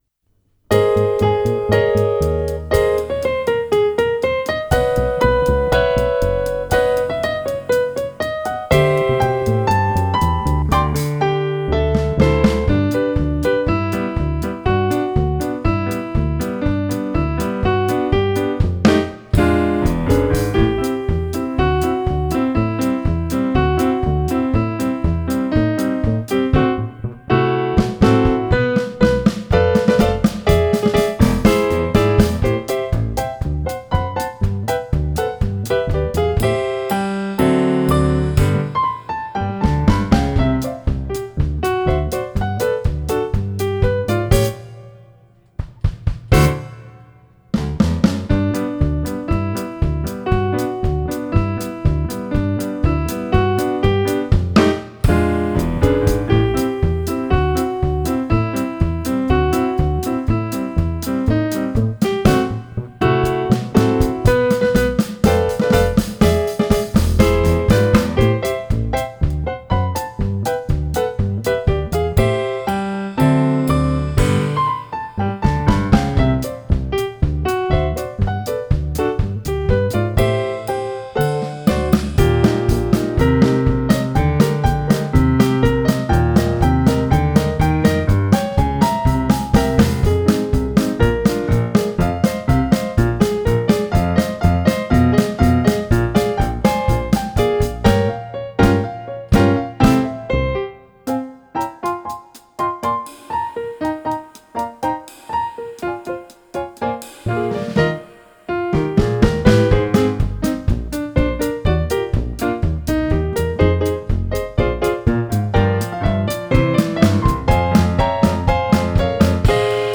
We_Need_A_Little_Christmas-backing.m4a